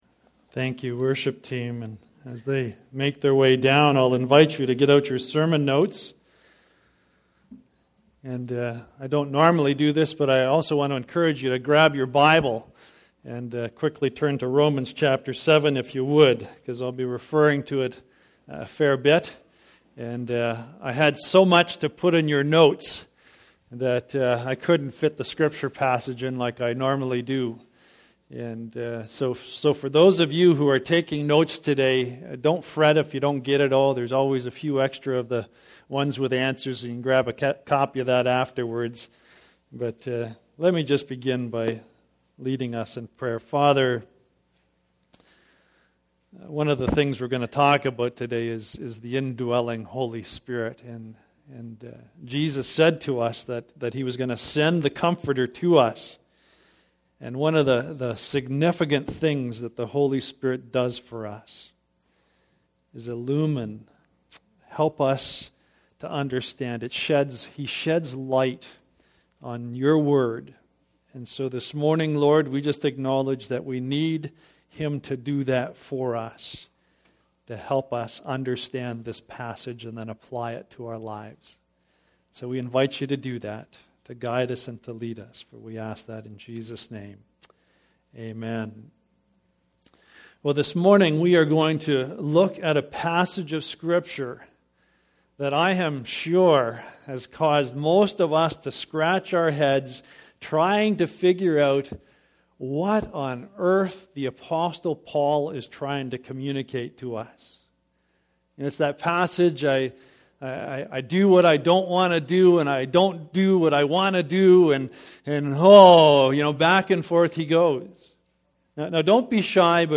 Sermons | Ritson Road Alliance Church